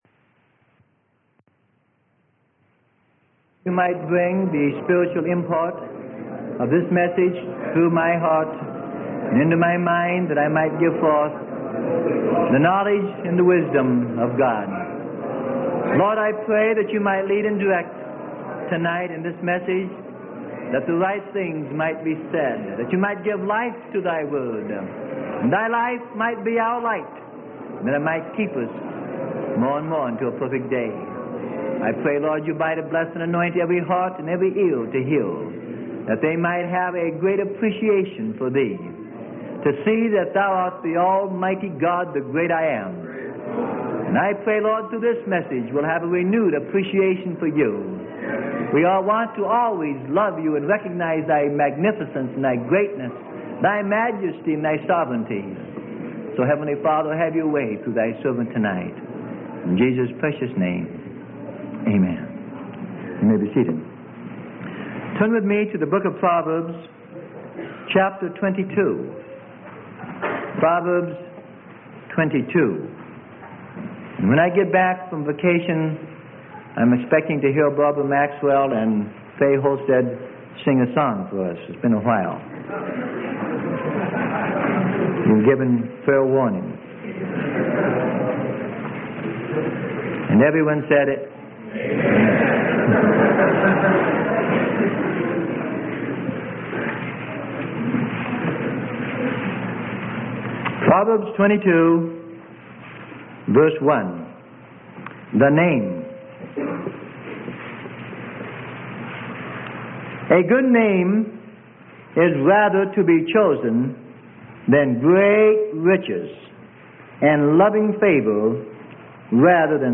Sermon: The Name - Freely Given Online Library